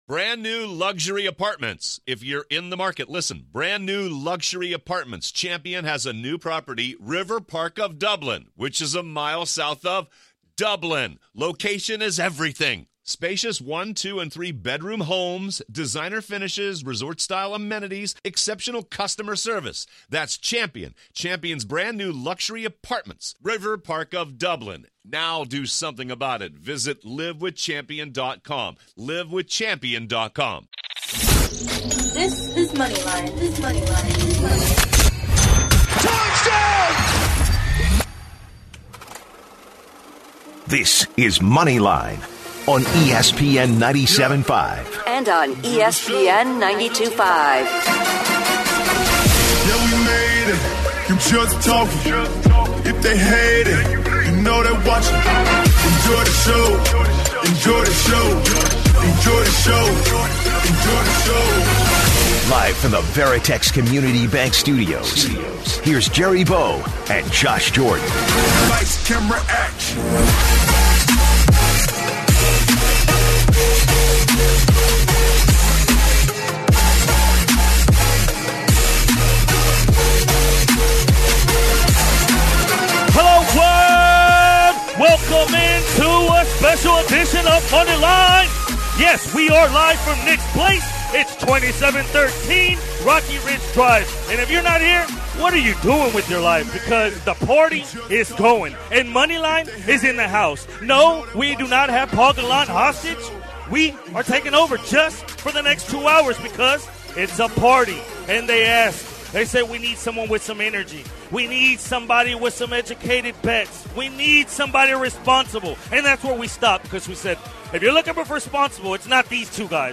live from Nick's Place